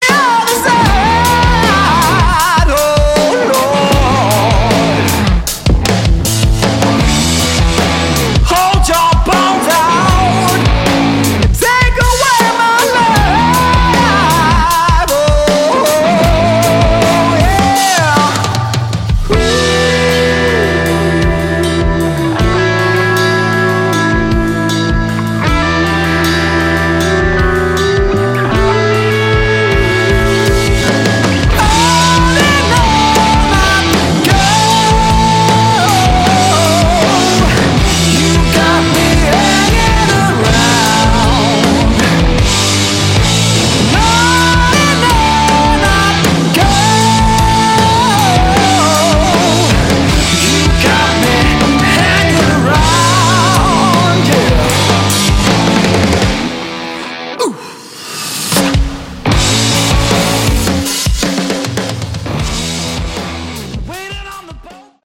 Category: Bluesy Hard Rock
vocals, harmonica
guitar, backing vocals
drums
bass